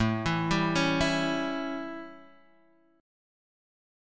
A7 Guitar Chord
So the notes are :A, C#, E and G.
The most common A7 Chord Shape
This sounds like this while streaming(standard tuning) :
A7-strumming-sound.mp3